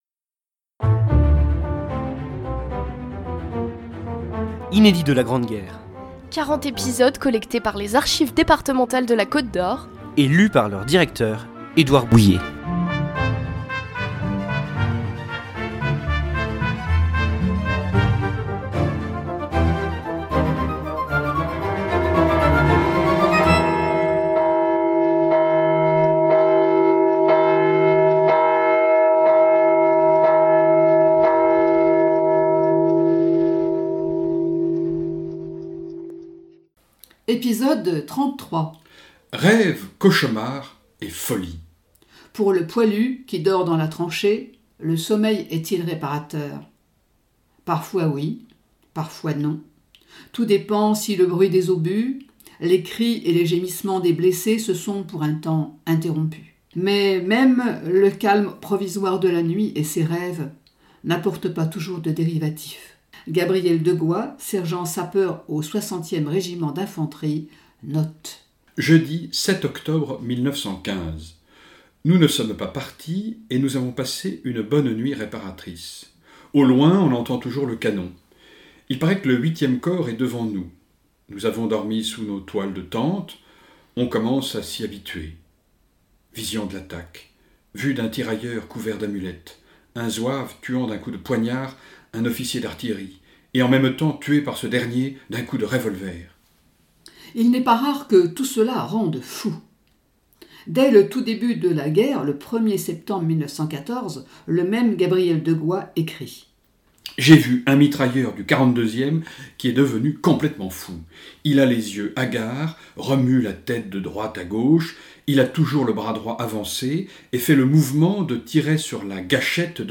Un feuilleton de lettres et de journaux intimes à suivre jusqu’au 11 novembre 2018, pour célébrer le centenaire de l’armistice de la Grande Guerre, signé à Rethondes le 11 novembre 1918 !